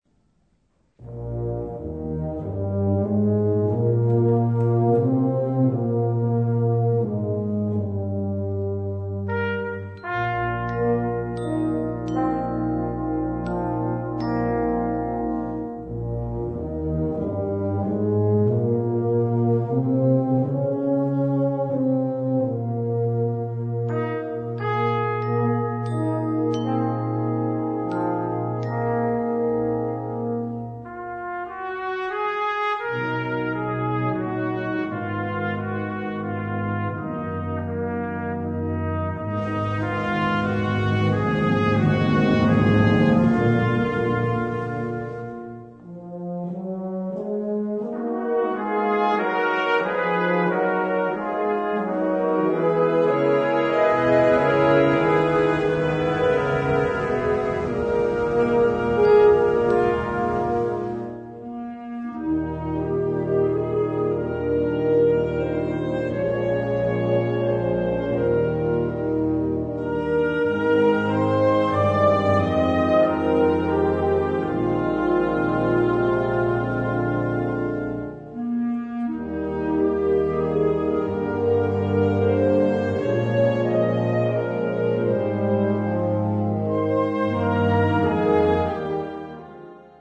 Kategorie Blasorchester/HaFaBra
Unterkategorie Suite
Besetzung Ha (Blasorchester)